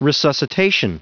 Prononciation du mot resuscitation en anglais (fichier audio)
Prononciation du mot : resuscitation